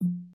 progress_loading_completed.mp3